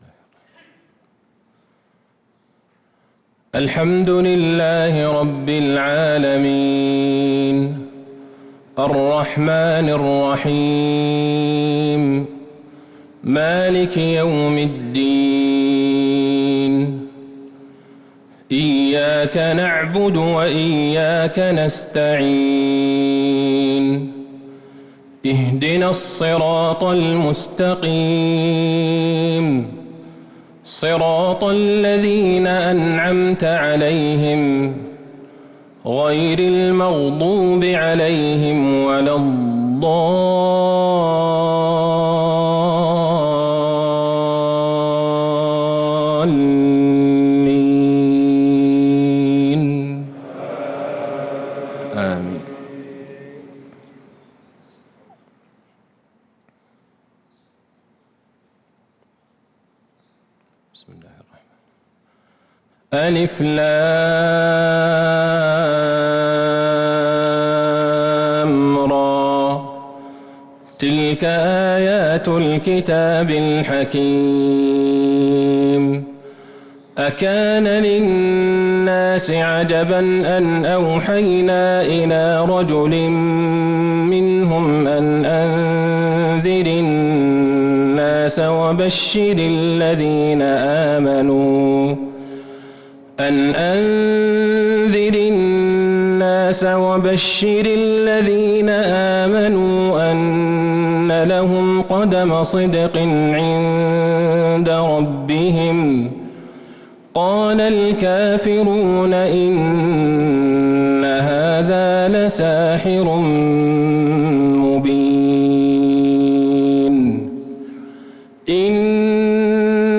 صلاة الفجر للقارئ عبدالله البعيجان 26 رمضان 1443 هـ